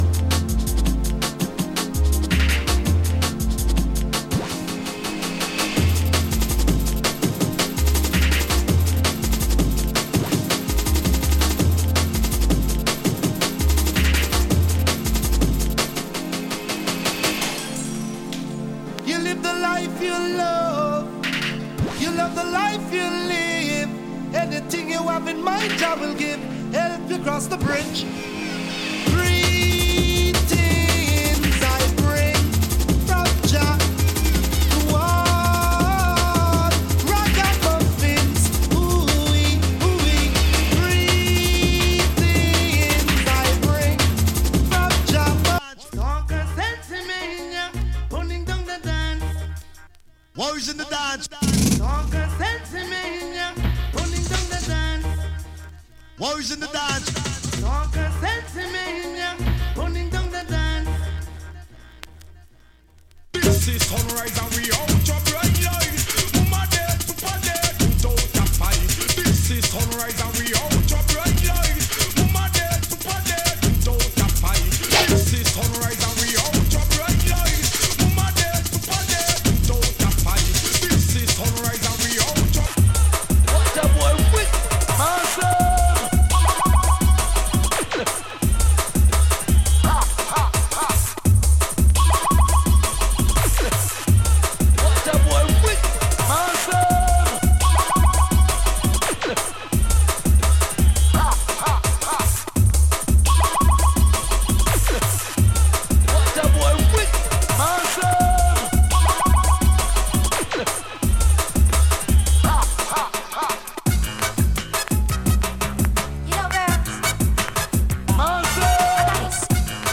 Category: Jungle